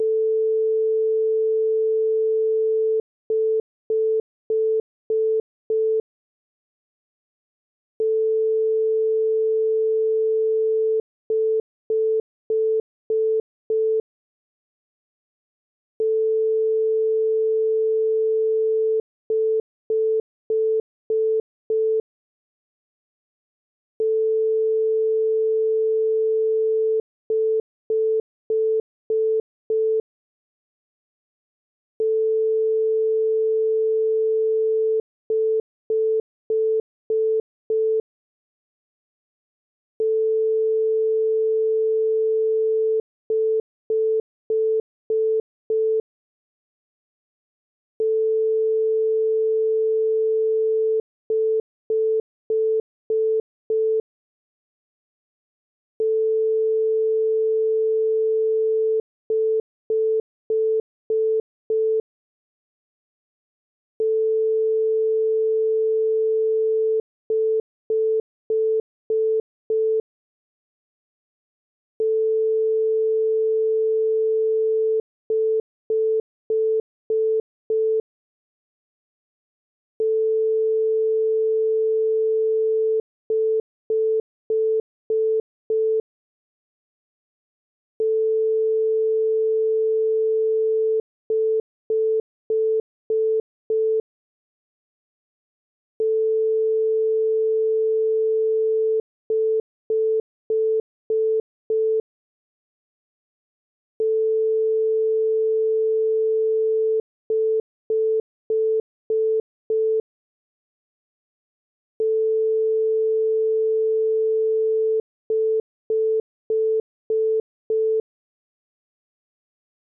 pulse-17.wav